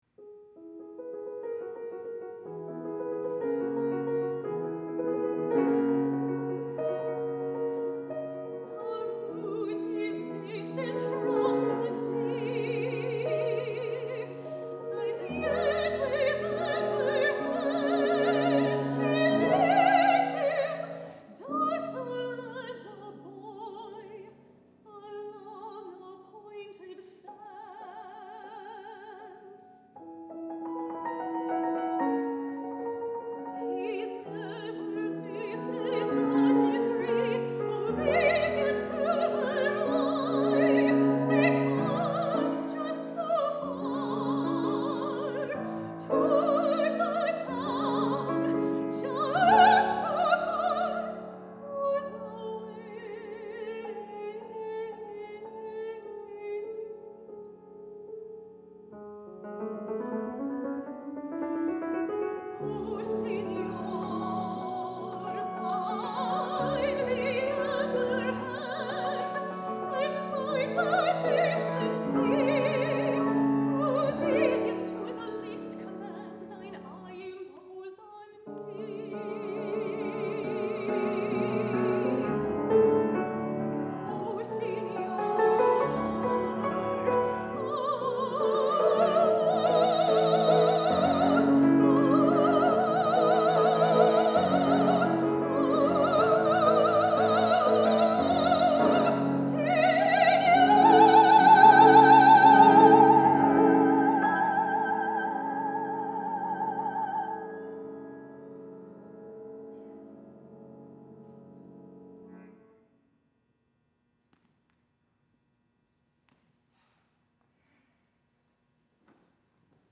for Soprano and Piano (2014)